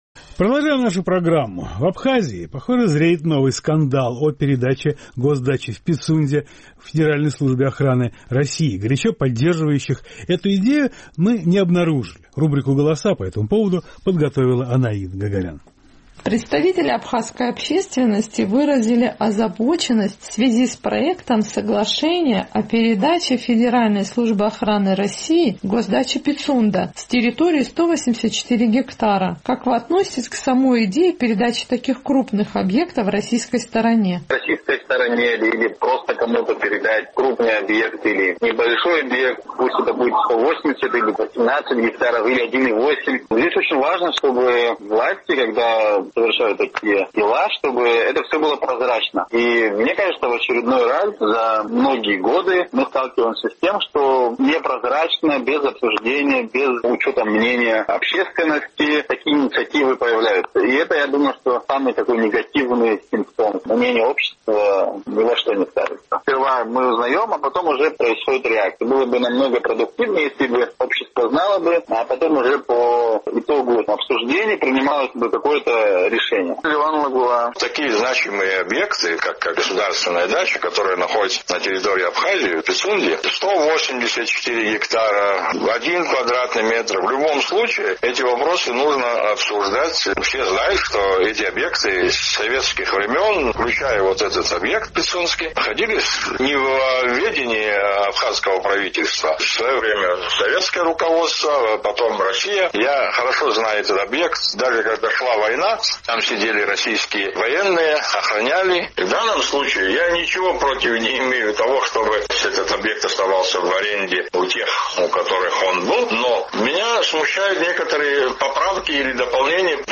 Горячо поддерживающих передачу госдачи «Пицунда» российской ФСО в ходе нашего сухумского опроса мы не обнаружили.